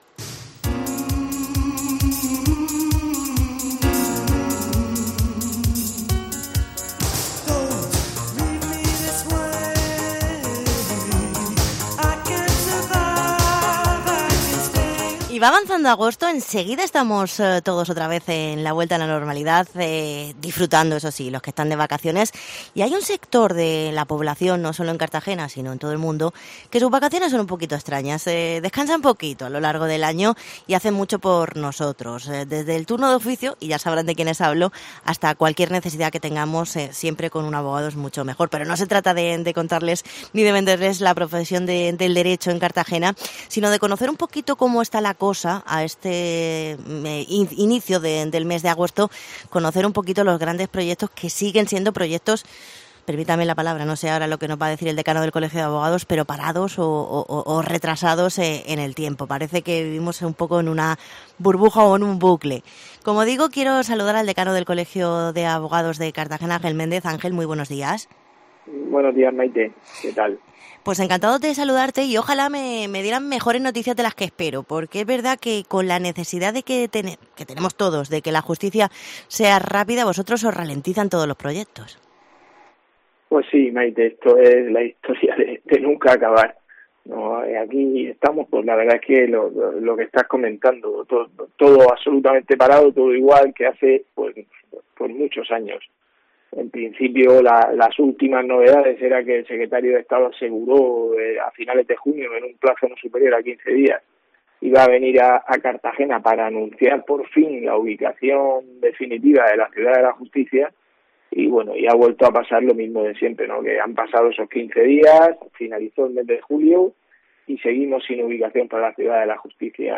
Entrevista al Decano del colegio de abogados